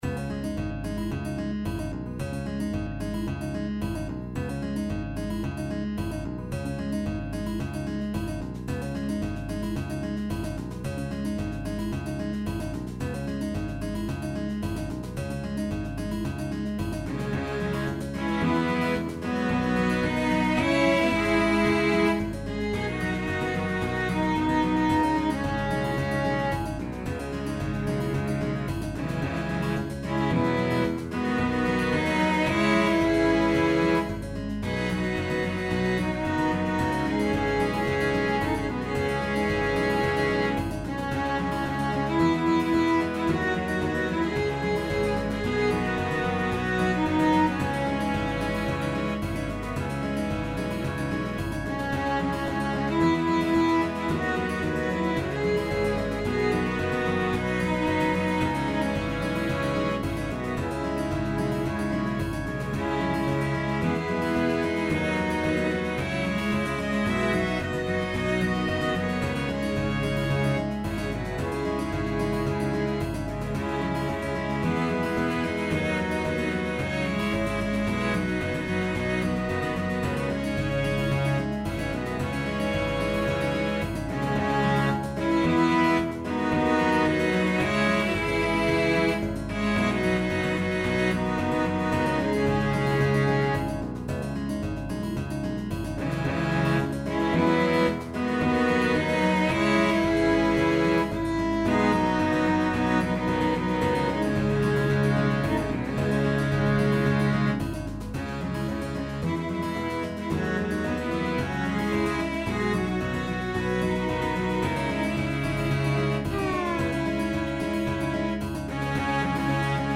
SATB + piano/band